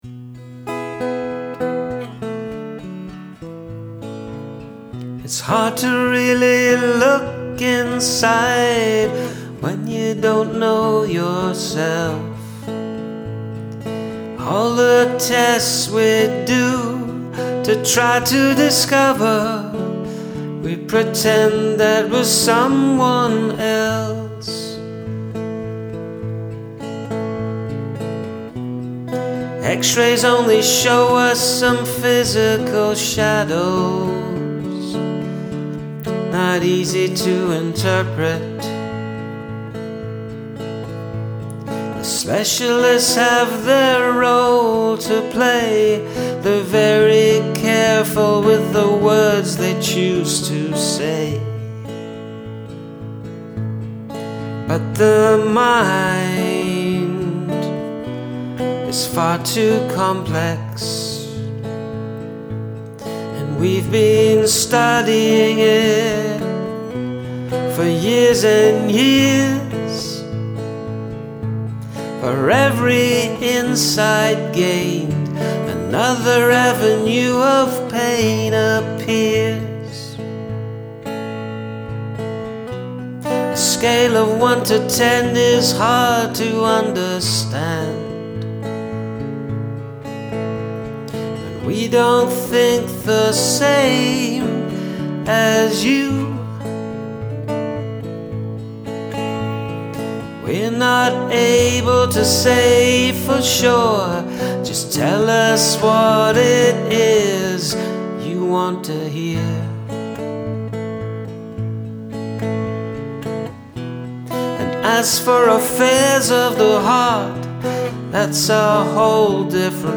Lovely lyrical delivery too.